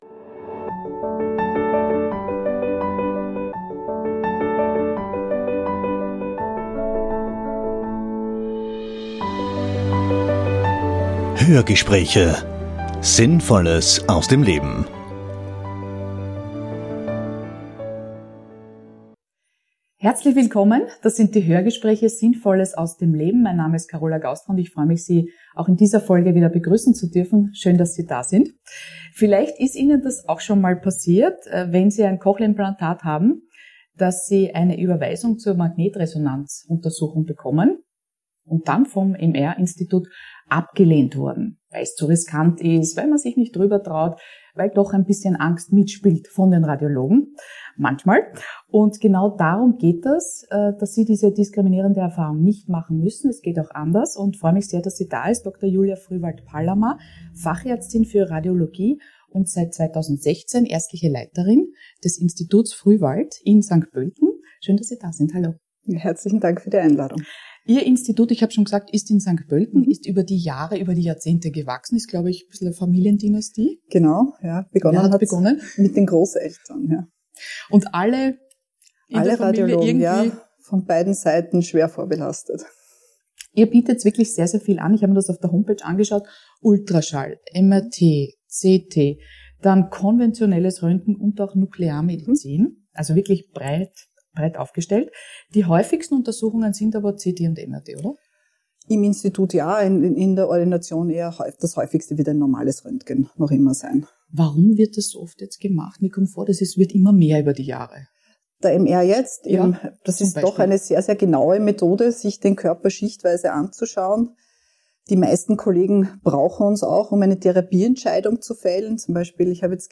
Ein persönliches Anliegen ihrerseits ist es, über die Funktionalität des MRTs mit implantierbaren Hörsystemen zu informieren, ihr Forschungswissen mit der Fachwelt zur teilen und hilfesuchende Menschen aufzuklären. Über den richtigen Ablauf, mögliche Tücken und wie positive Erfahrungen diese Untersuchung zur Routine werden lassen, erzählt die sympathische Radiologin im Interview.